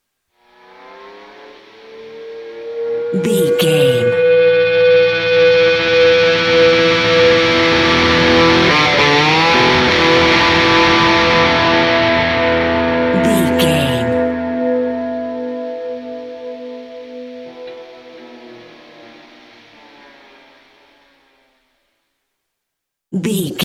Ionian/Major
electric guitar
Slide Guitar